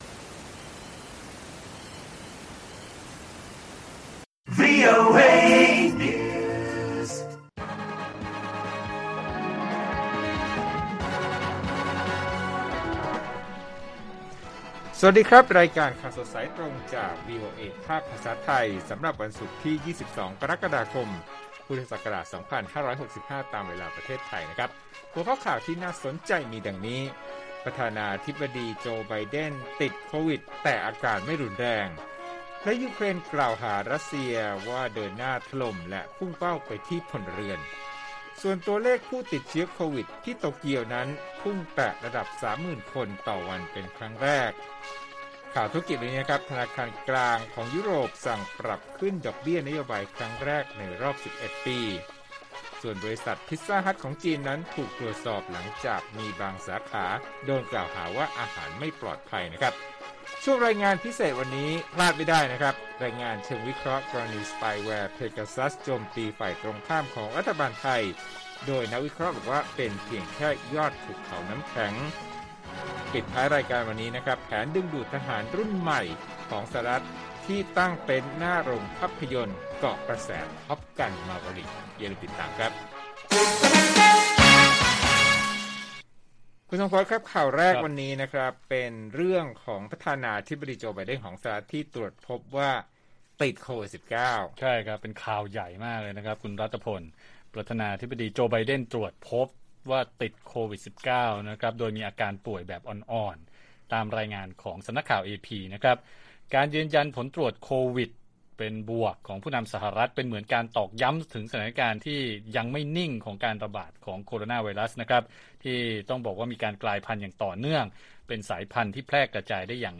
ข่าวสดสายตรงจากวีโอเอไทย 6:30 – 7:00 น. วันที่ 22 ก.ค. 65